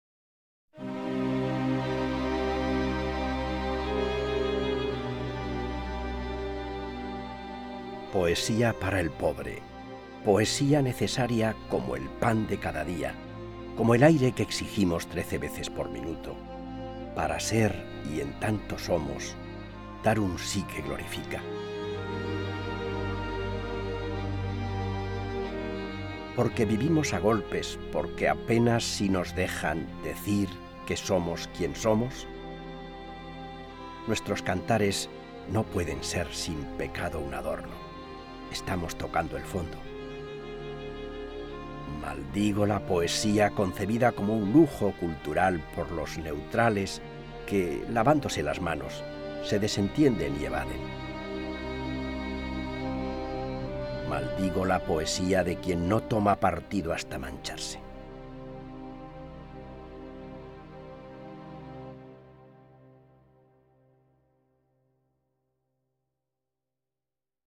recitar